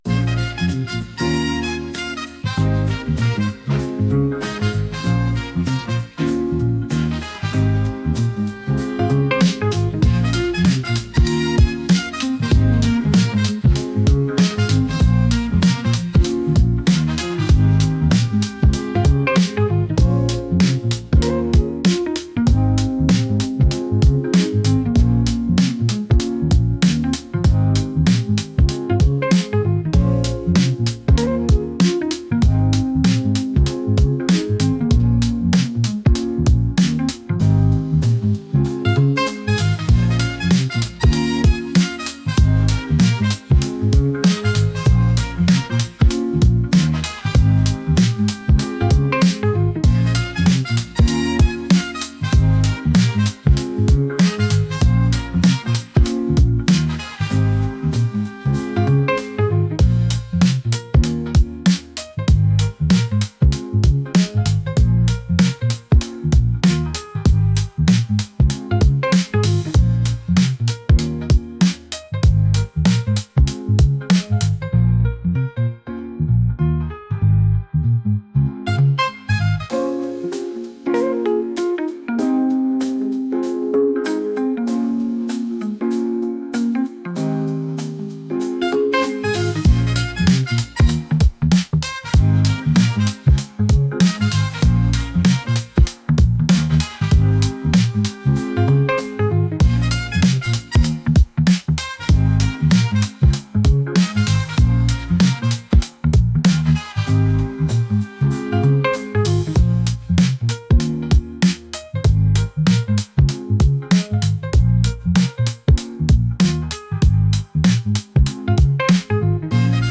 Música del Menú